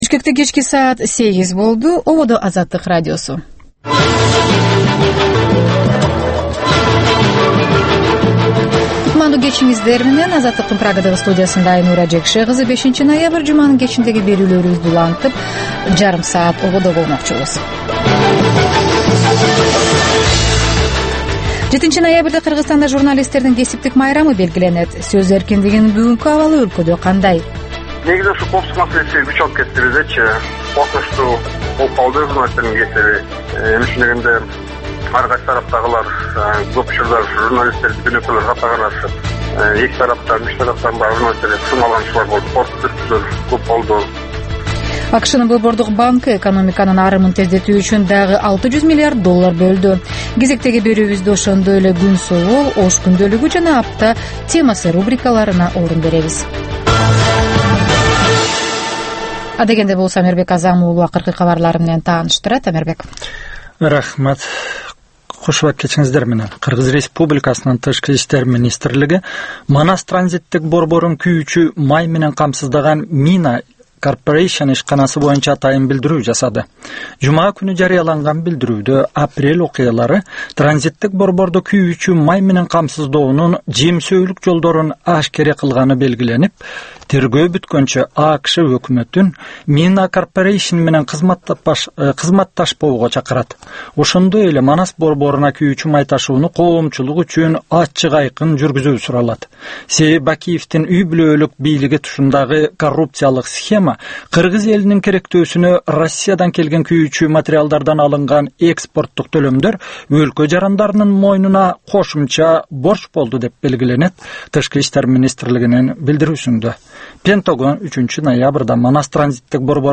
Кечки 8деги кабарлар